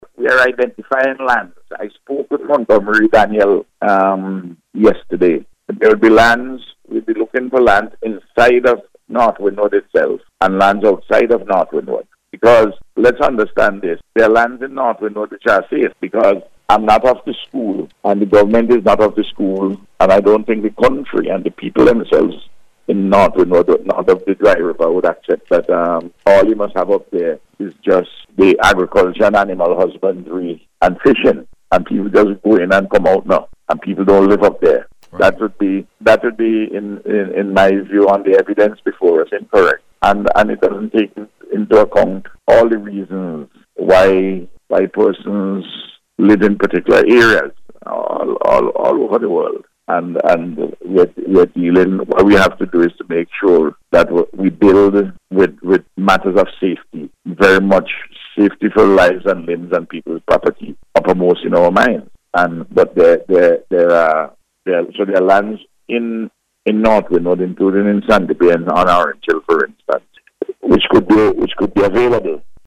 The Prime Minister made the disclosure, during NBC’s Eyeing La Soufriere Programme on Monday.